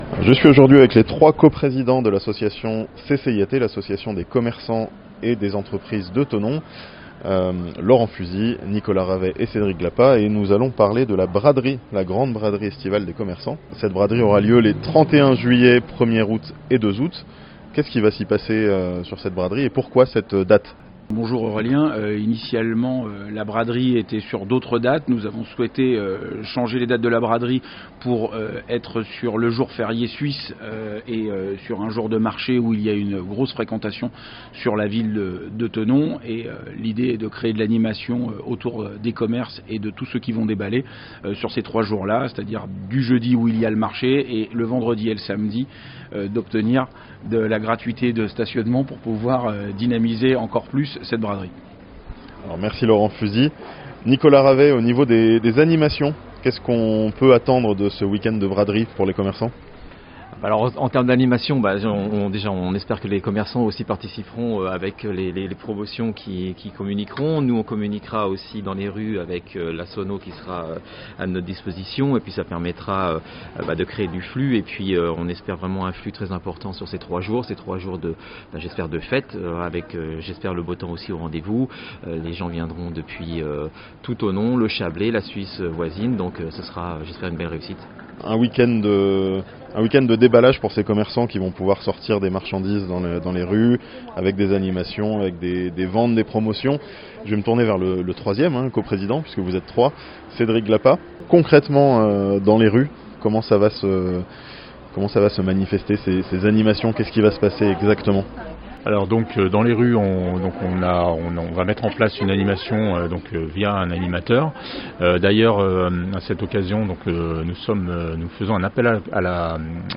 Trois jours de braderie à Thonon (interview)